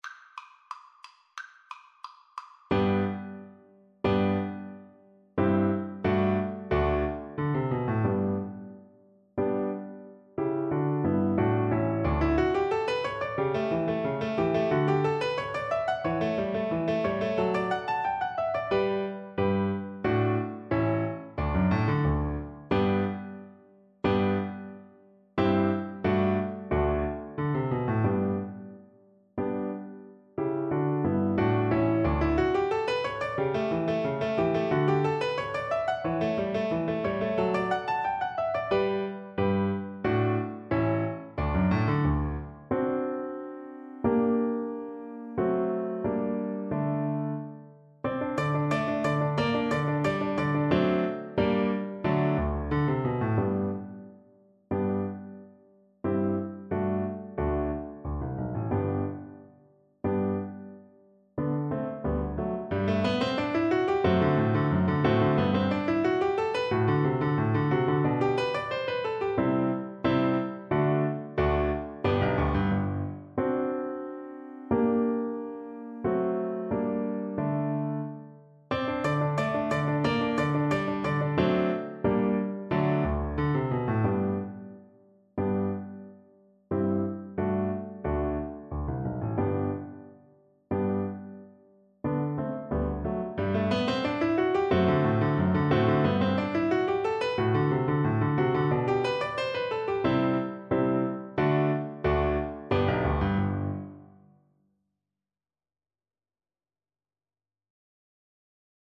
Classical Clementi, Muzio Sonatina Op 36, No 1, First Movement Viola version
Play (or use space bar on your keyboard) Pause Music Playalong - Piano Accompaniment Playalong Band Accompaniment not yet available transpose reset tempo print settings full screen
Viola
G major (Sounding Pitch) (View more G major Music for Viola )
2/2 (View more 2/2 Music)
Spiritoso Spiritoso = 180
Classical (View more Classical Viola Music)